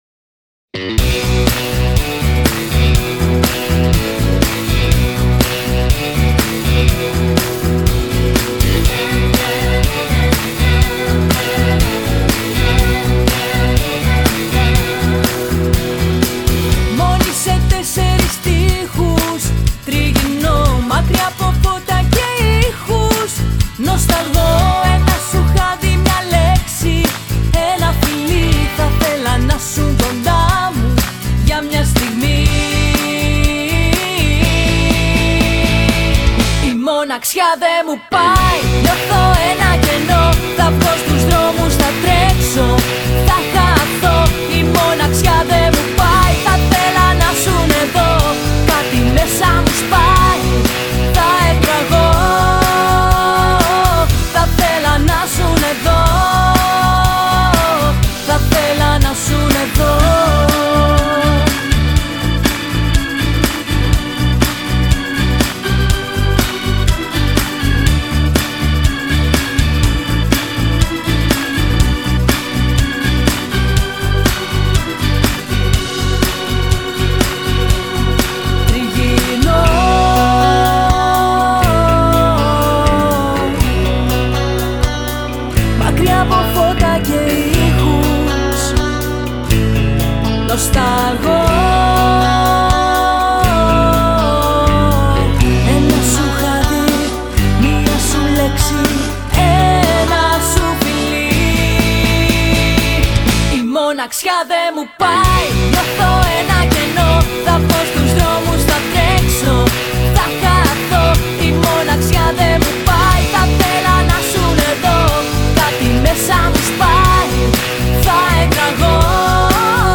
Quality: 192kbps, 44kHz, stereo
Style: Pop music